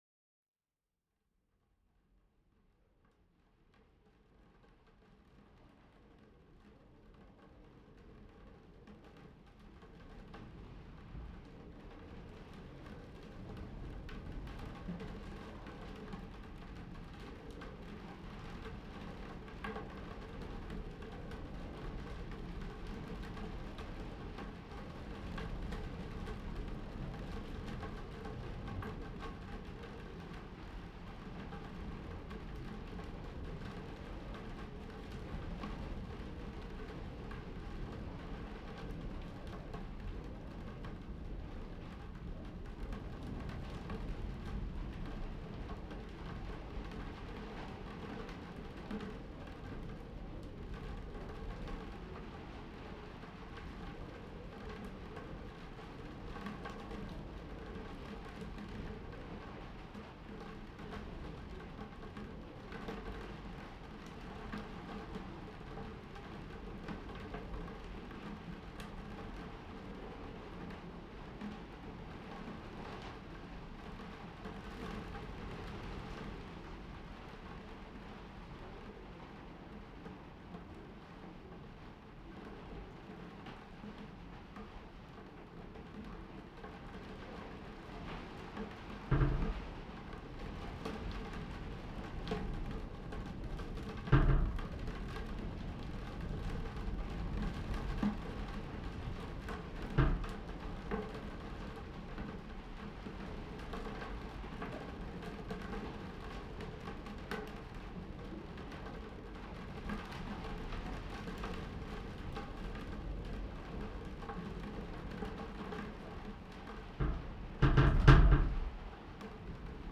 The weather in Iceland so far this summer has been both cold and windy, and although I am much better equipped for traveling today than I was last century, I felt the need to seek shelter at the house this summer. There were quite familiar sounds in the house that I recorded there in 3 different places.